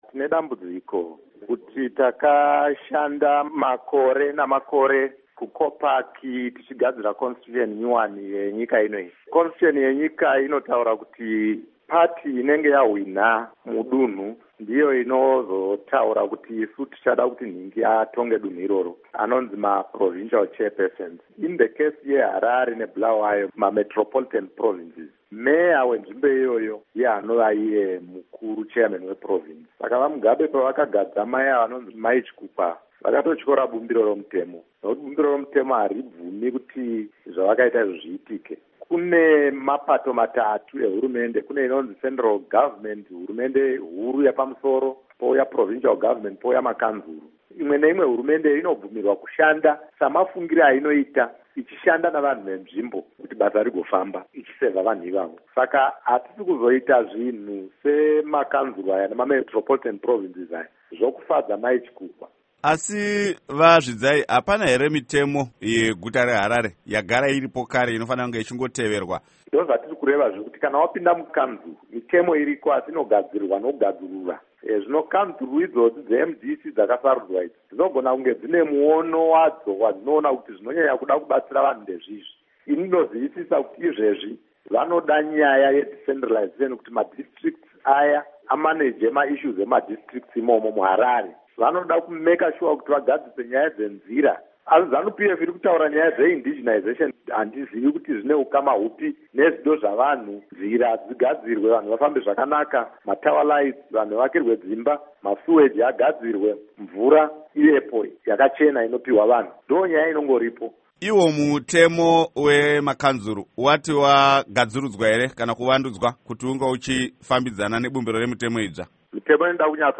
Hurukuro naVaSesel Zvidzai